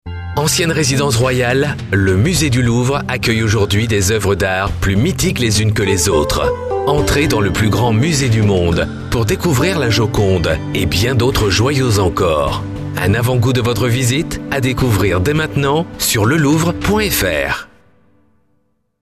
French male voice talent
Sprechprobe: Industrie (Muttersprache):